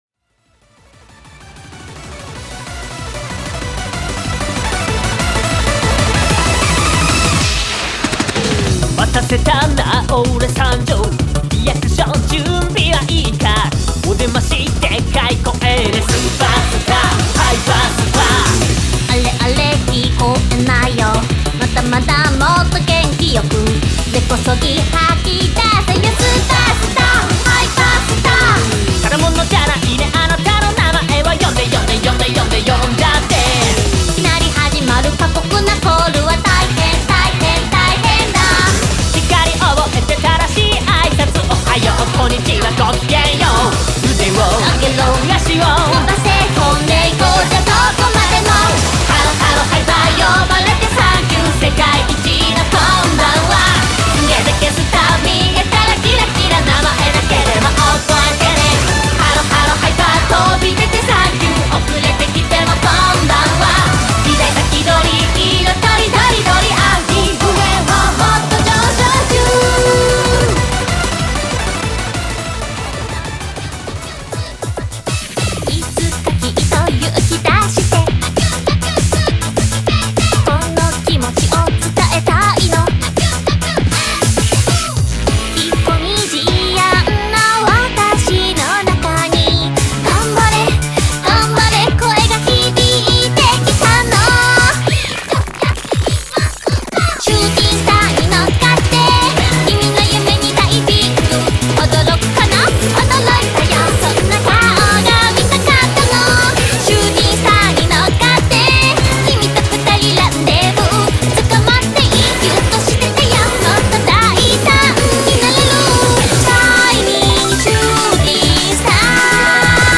クロスフェードMP3（6.62 MB）